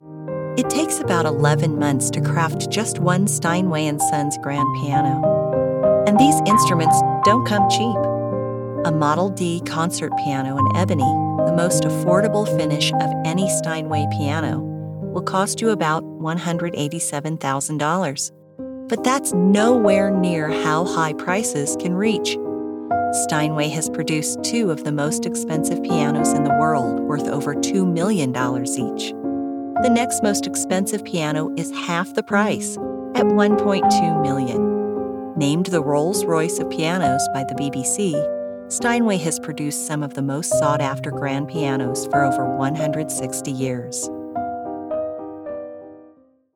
Video Narration - Steinway